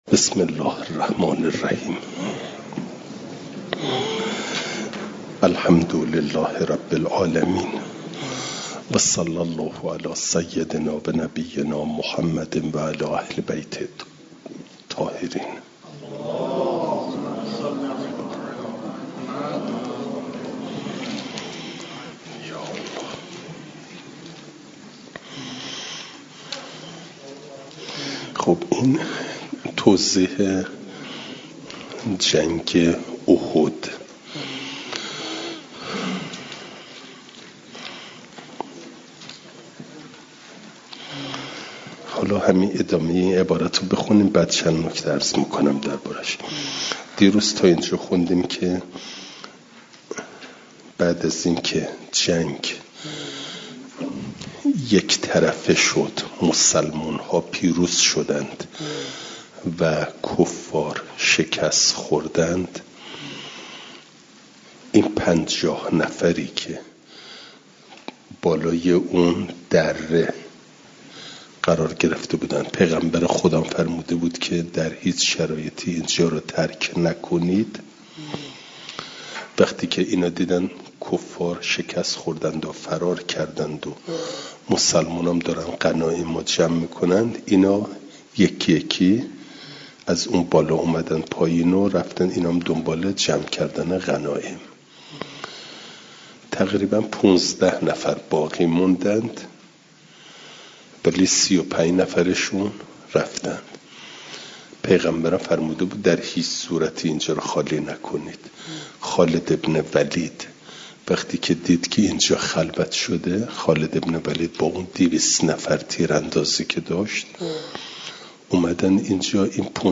فایل صوتی جلسه سیصد و سوم درس تفسیر مجمع البیان